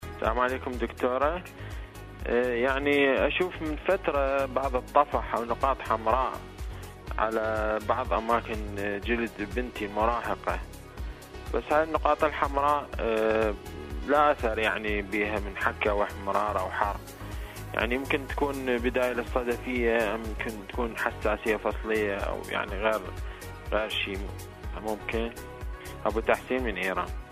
أنتم وطبيب الأسرة /مشاركة هاتفية